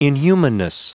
Prononciation du mot inhumanness en anglais (fichier audio)
Prononciation du mot : inhumanness